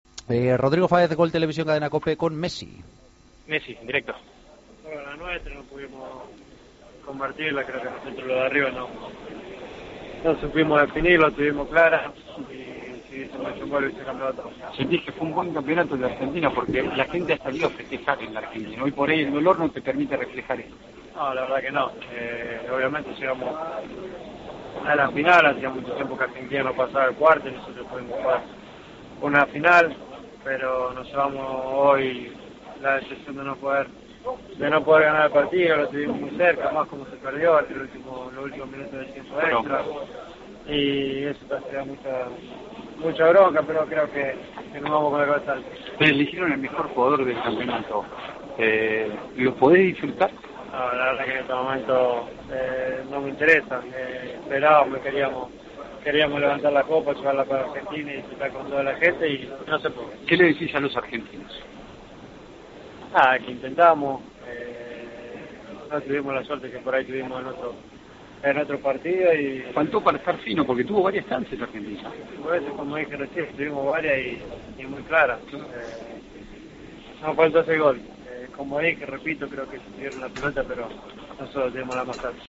La estrella argentina habló de la final y del trofeo al mejor jugador ganado: "Hacía mucho tiempo que Argentina no pasaba de cuartos, pero nos llevamos la decepción de no ganar. En este momento el premio a mejor jugador no me interesa. No tuvimos la suerte de otros partidos".